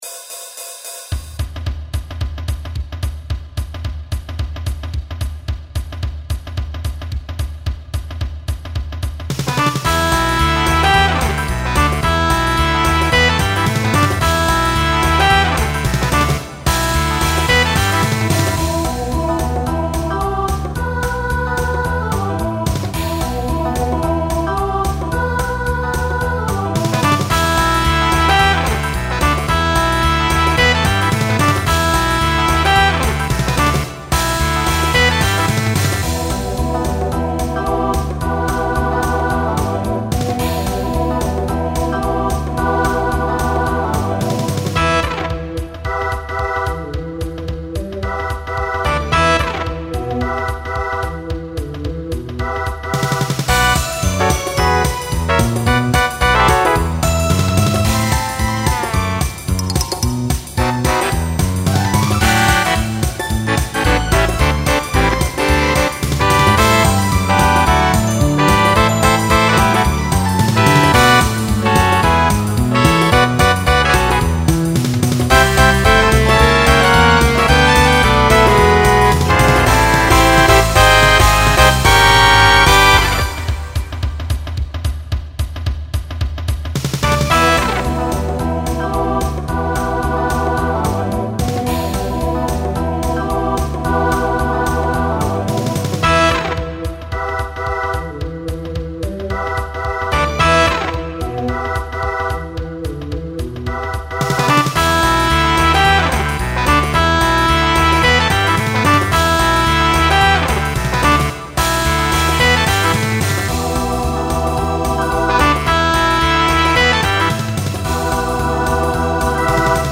Swing/Jazz Instrumental combo
Story/Theme Voicing SATB